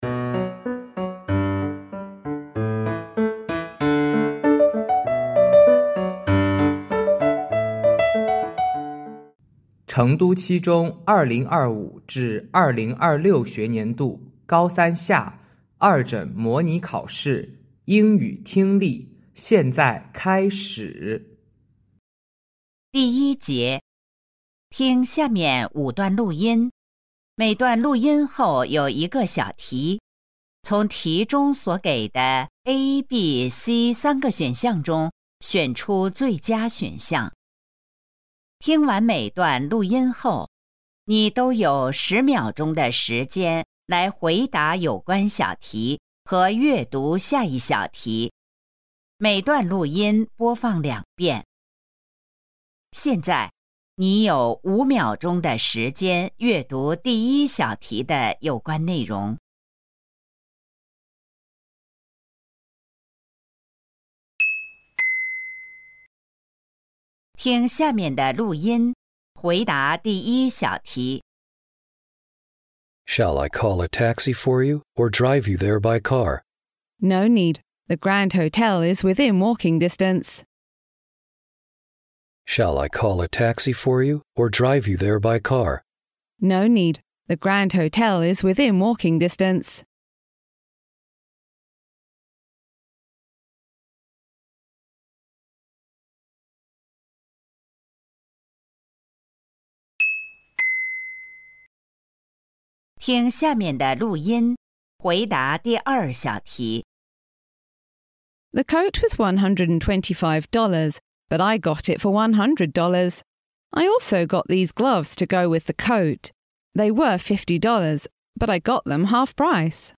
成都七中2026届高三二诊模拟考试英语听力.mp3